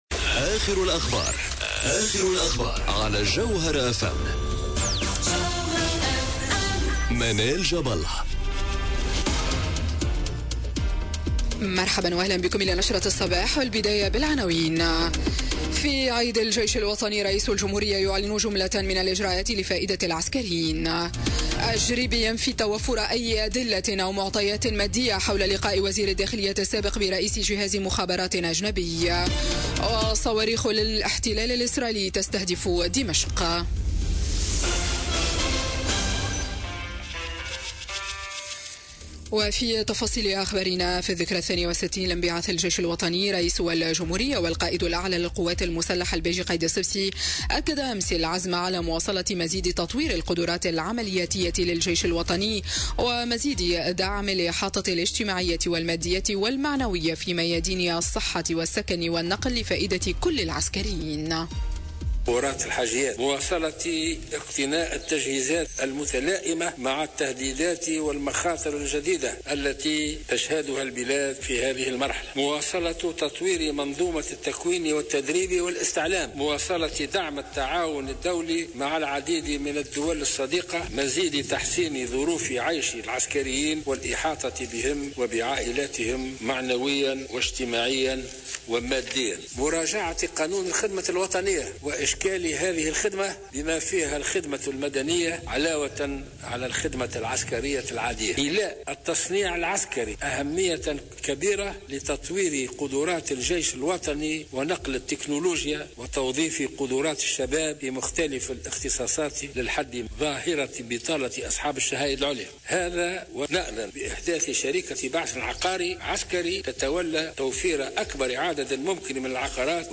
نشرة أخبار السابعة صباحا ليوم الثلاثاء 26 جوان 2018